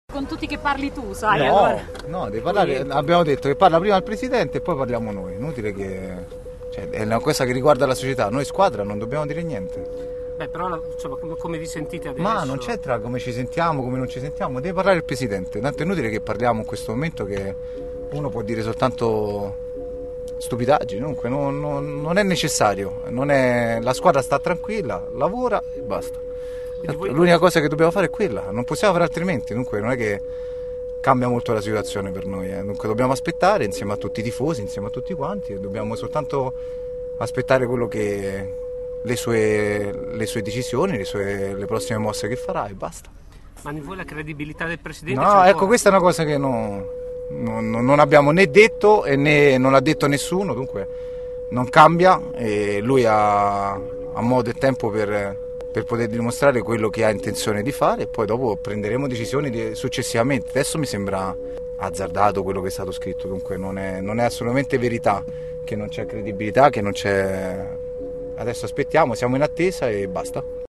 Il capitano lasciando l’allenamento è stato attorniato da cronisti e tifosi e ha rilasciato alcune dichiarazioni. In sostanza con linguaggio diplomatico, Di Vaio rilancia la palla: “deve parlare il presidente, noi lavoriamo e aspettiamo.”